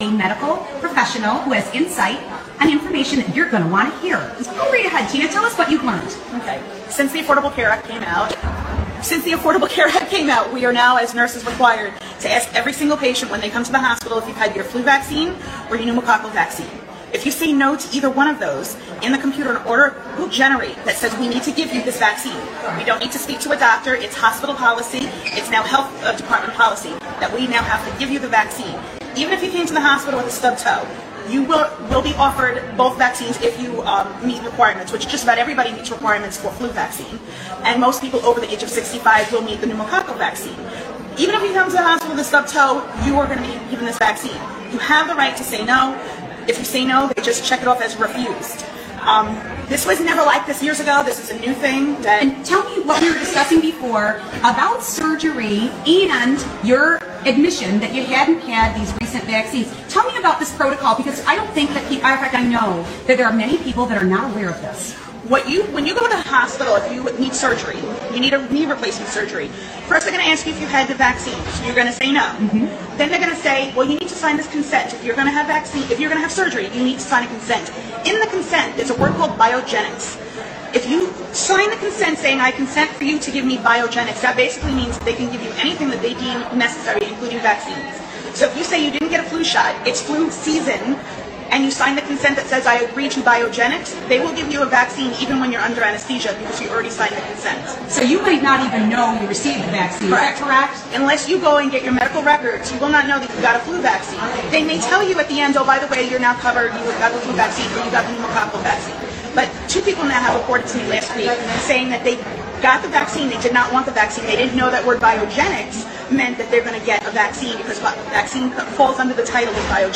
Video Description: Interview with Nurse – Affordable Care Act 📜